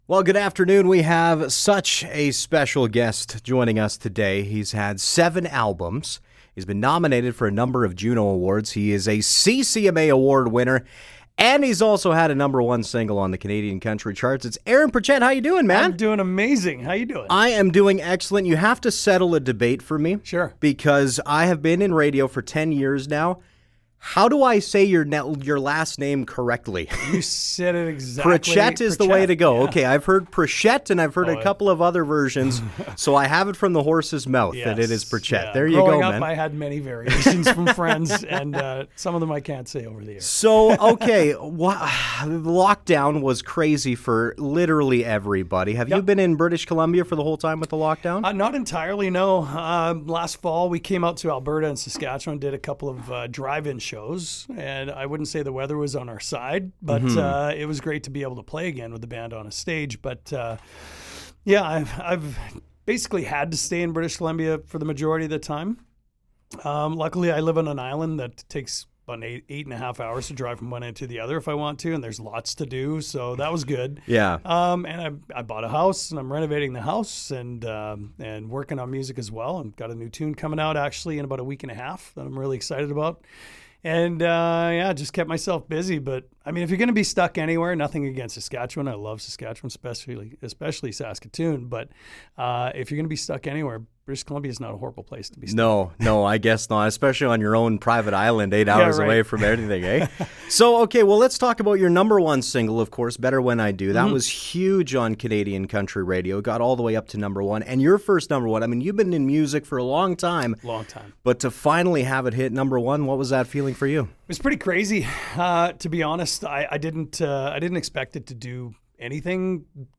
Aaron Pritchett Joins Us In Studio Before His Show at the Saskatoon Ex
aaron-pritchett-interview-august-6th-2021.mp3